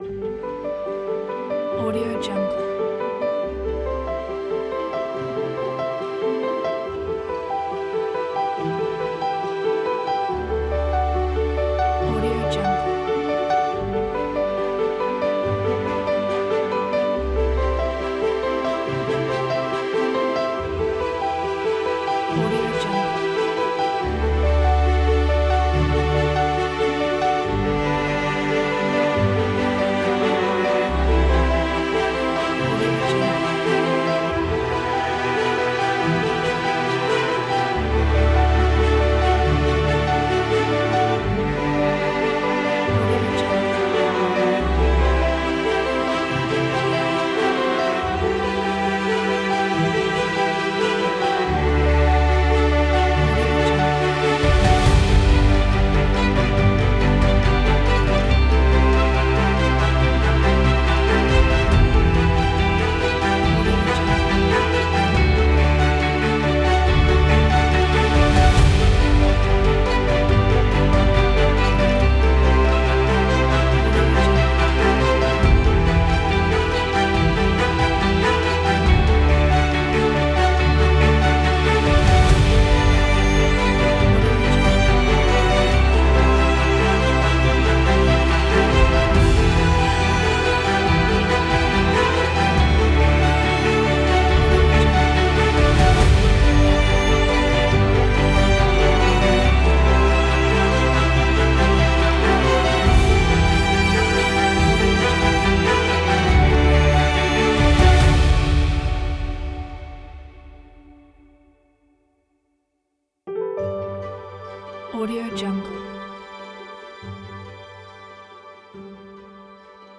دانلود موسیقی بی‌کلام الهام بخش ارکستر زهی عروسی
سینمایی